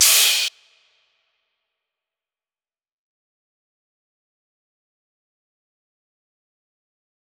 Crashes & Cymbals
DMV3_Crash 1.wav